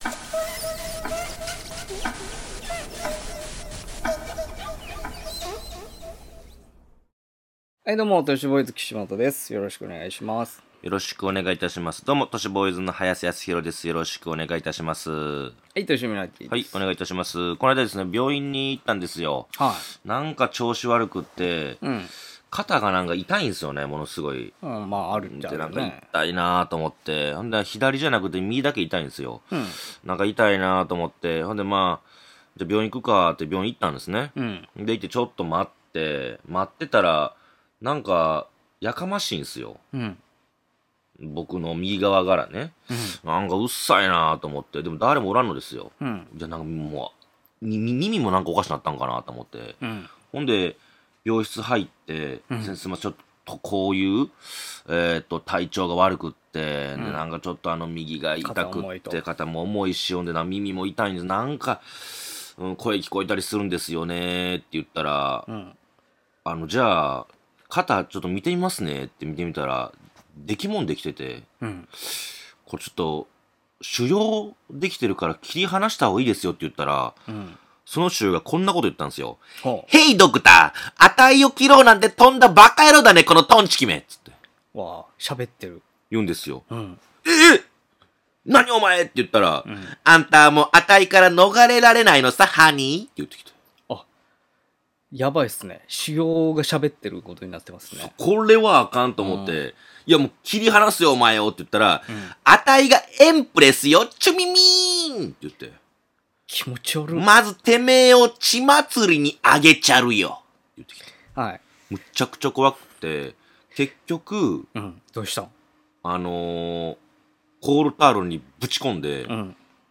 若手放送作家の２人が都市伝説や日常に忍び寄るオカルト又は眉唾な噂話を独自の目線で切りお送りしていく番組です。